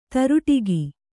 ♪ taruṭigi